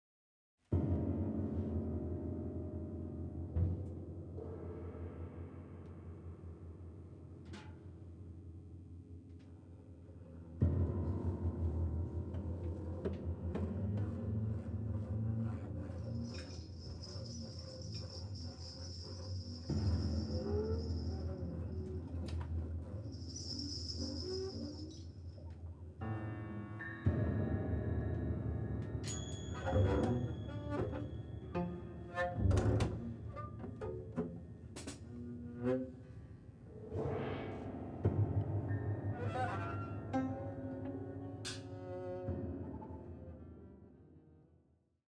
Recorded on at Jazz Spot Candy
いつものように何も決めずに互いに刺激し合い自由にその場で曲を創り上げる。